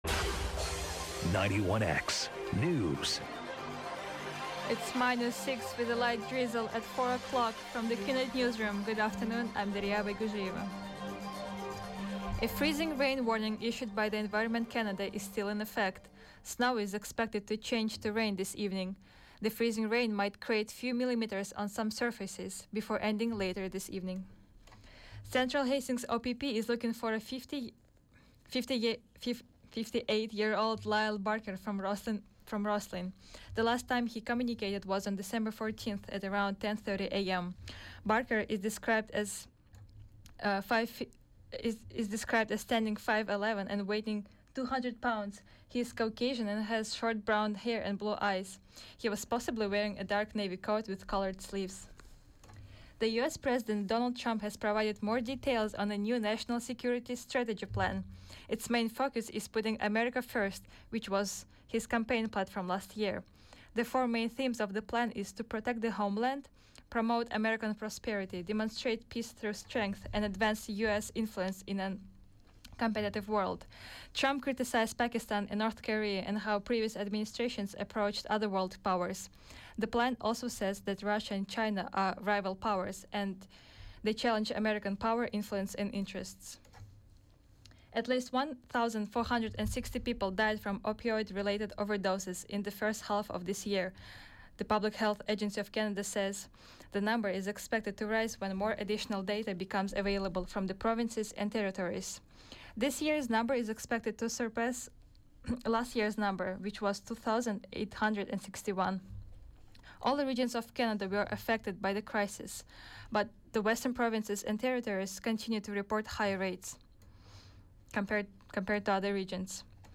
91X Newscast: Monday, Dec. 18 2017, 4 p.m.